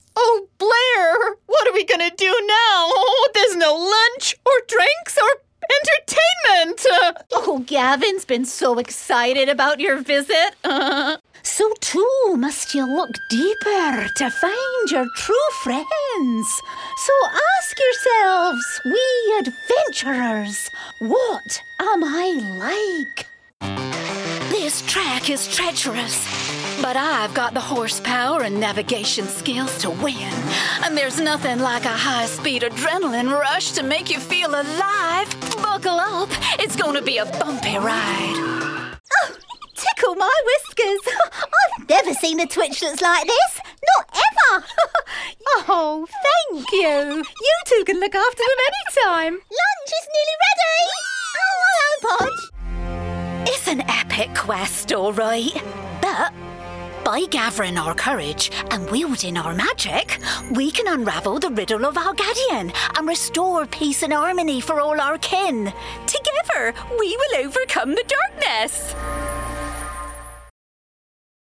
A Classic English Rose Voice
Animation Demo
English RP, Northern, Liverpool, London, West Country, General American, Australian.
Middle Aged
New Animation Demo.mp3